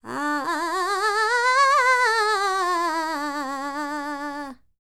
QAWALLI 01.wav